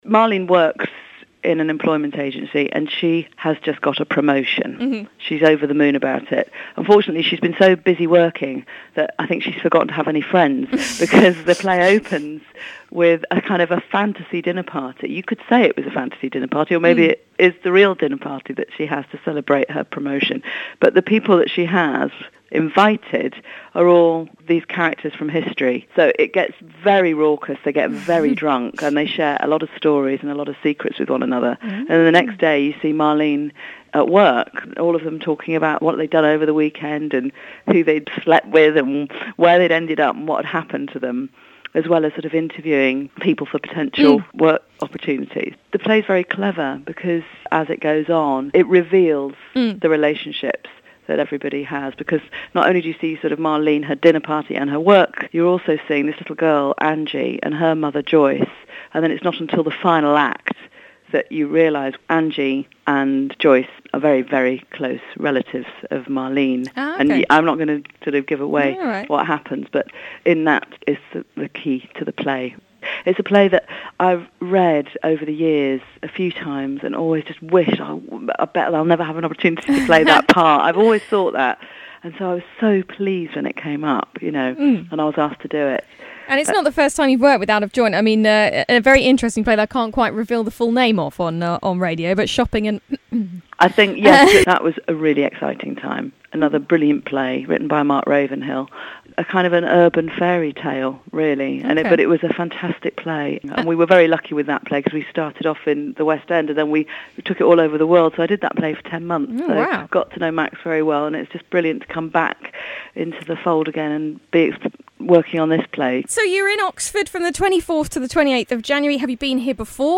Glide's Morning Glory interview Caroline Catz(Part2)